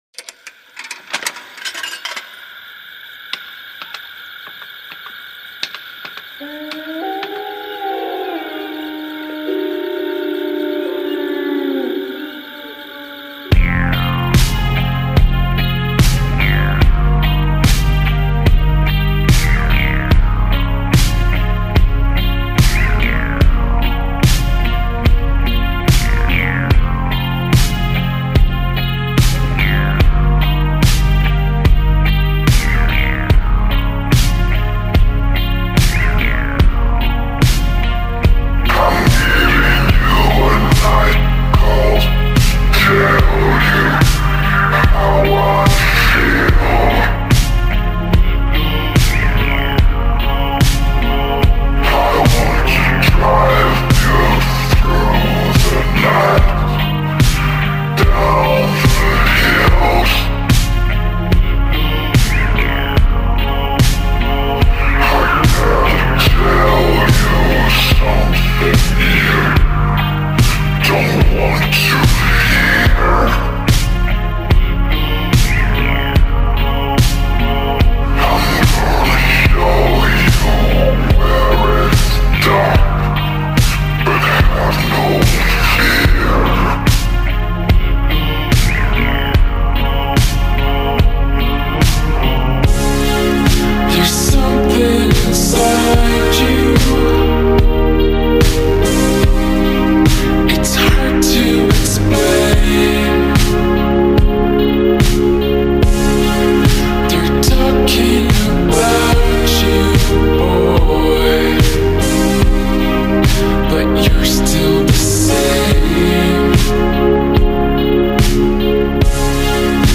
در نسخه Slowed مناسب ادیت با فضایی شبانه در ریتمی کند